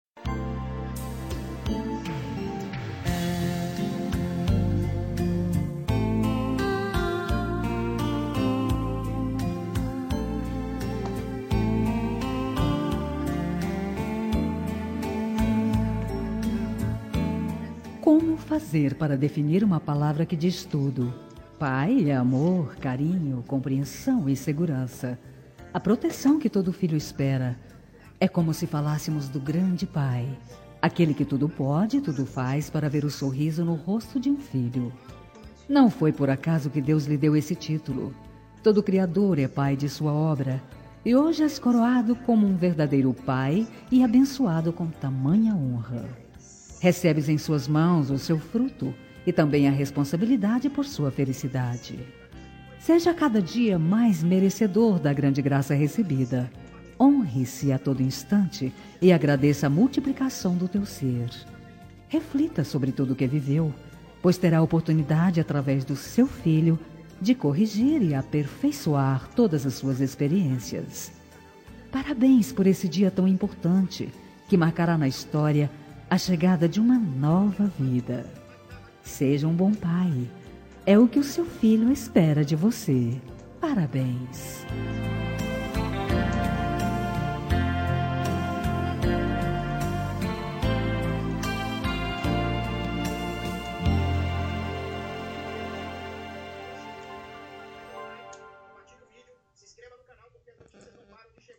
Telemensagem de Paternidade – Voz Feminina – Cód: 6619